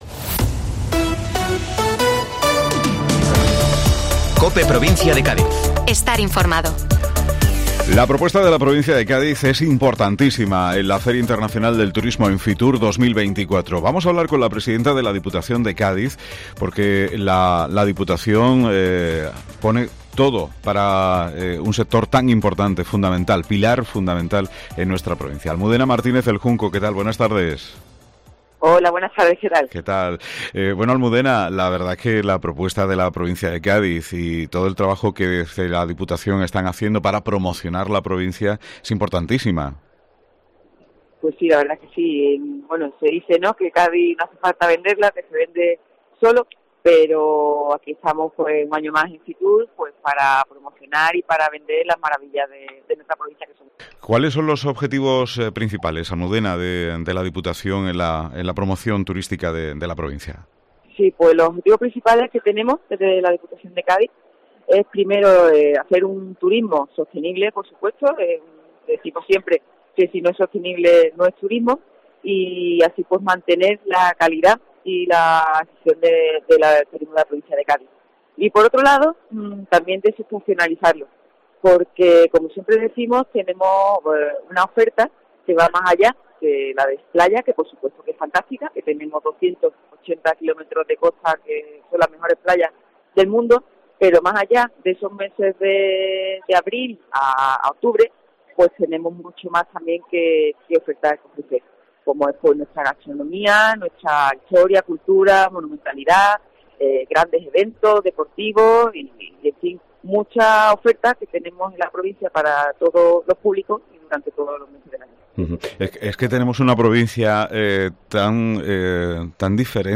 Almudena Martínez del Junco, Presidenta de la Diputación de Cádiz - FITUR 2024